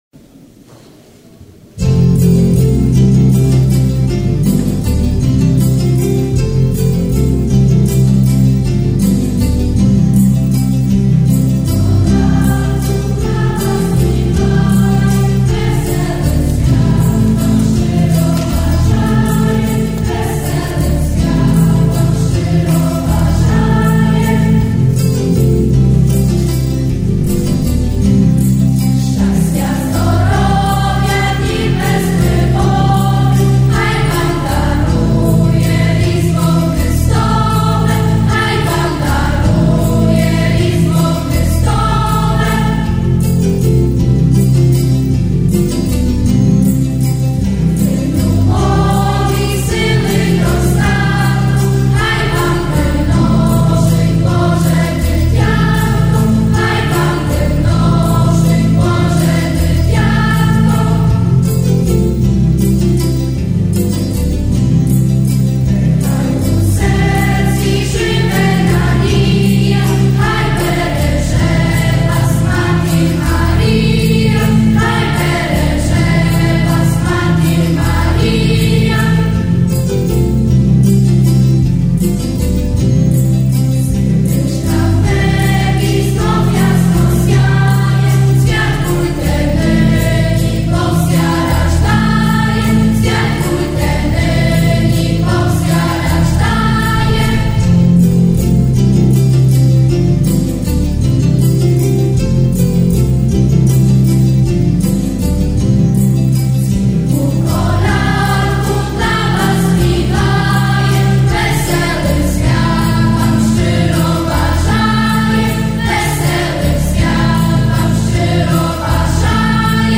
традиційний різдвяний концерт
Дитячого вокального ансамблю
Цього разу глядачі не підвели юних виконавців і численно залишилися послухати рідісний, емоційний спів.